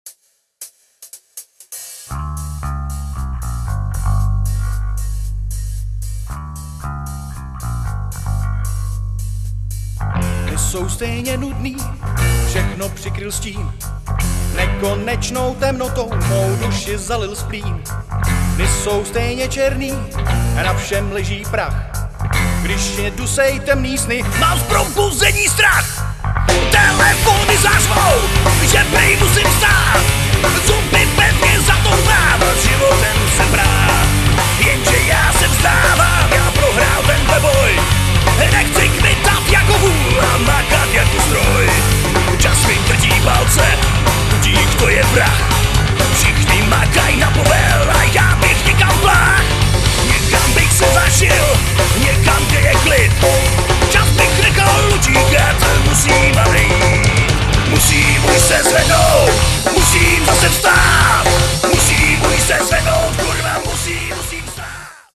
zpěv
kytara
bicí